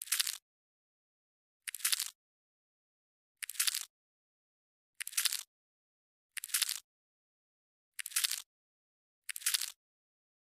Звуки богомола
еще богомол умеет издавать такие звуки